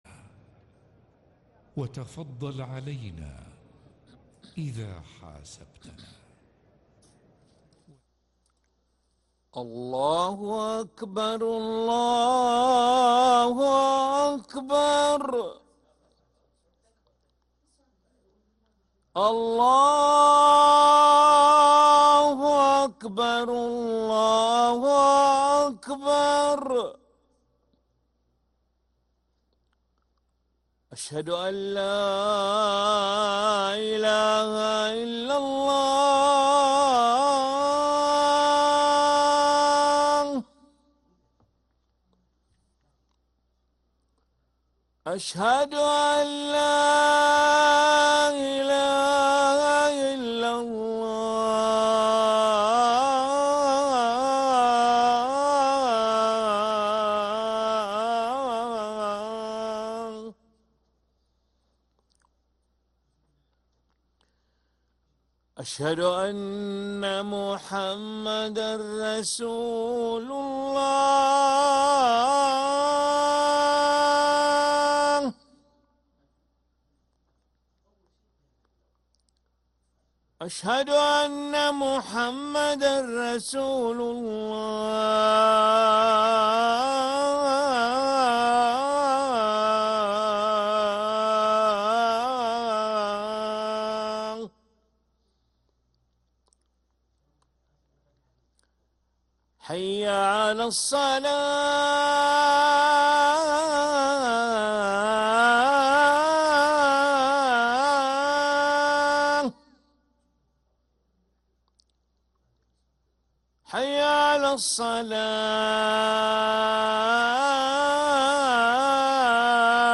أذان العشاء للمؤذن علي ملا الخميس 9 ربيع الأول 1446هـ > ١٤٤٦ 🕋 > ركن الأذان 🕋 > المزيد - تلاوات الحرمين